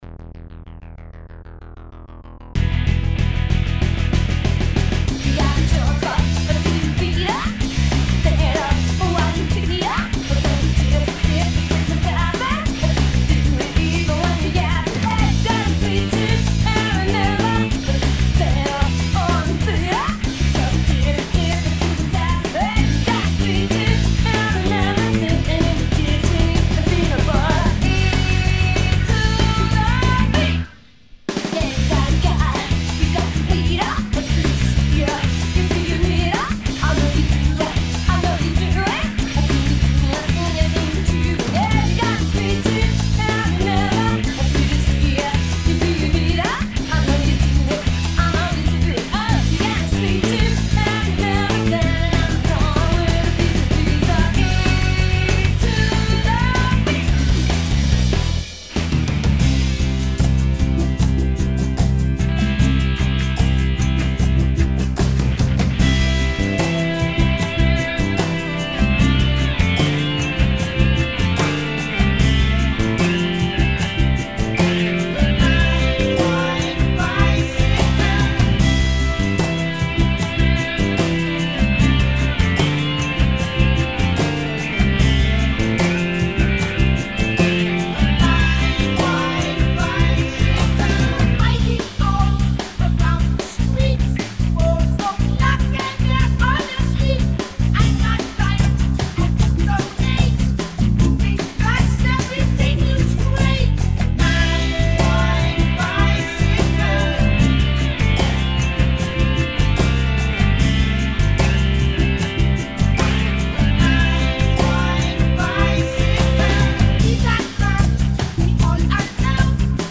is a mashup